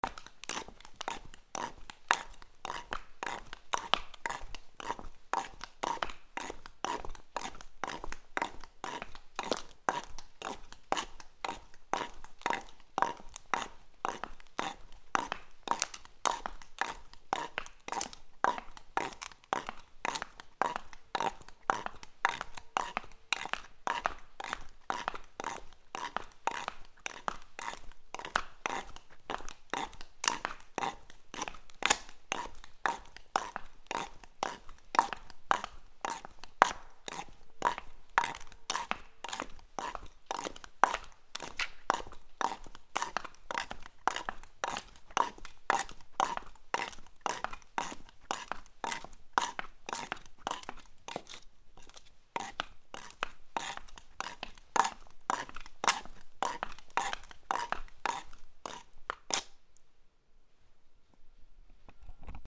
/ I｜フォーリー(足音) / I-240 ｜足音 特殊1
『ココ』
ゲタ コンクリートの上を少し早めに歩く